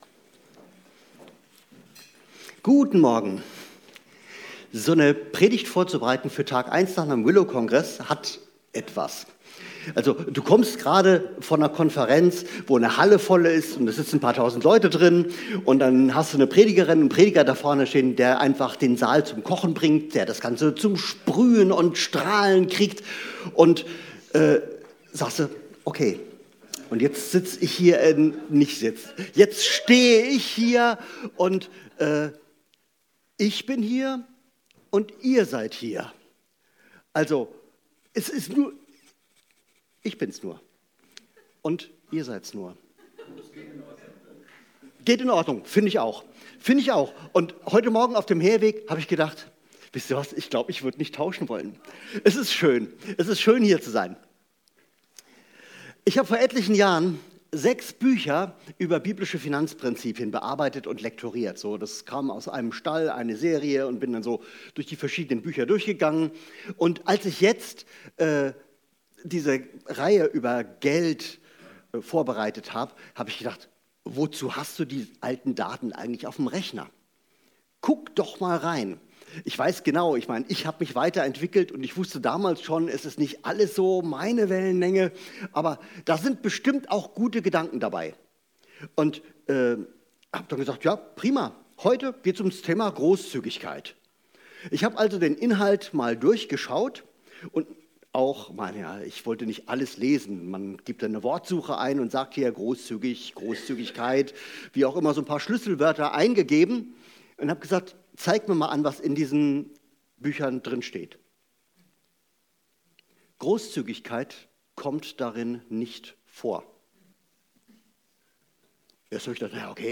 Tatsache ist ist auf jeden Fall: Es ist ein wichtiges Alltagsthema und wird (abgesehen von Spendenaufrufen) eher selten in Gemeinden besprochen. Das wollen wir ändern und machen weiter mit einer Predigt über großzügiges Geben, das nicht nur die Welt, sondern auch die gebende Person verändert